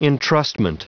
Prononciation du mot entrustment en anglais (fichier audio)
Prononciation du mot : entrustment